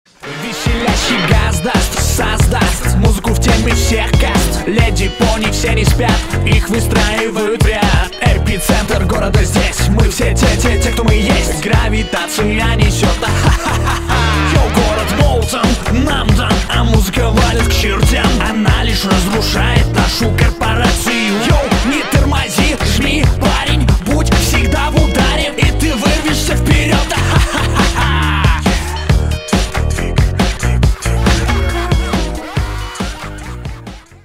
• Качество: 192, Stereo
гитара
Cover
Alternative Rock
Alternative Hip-hop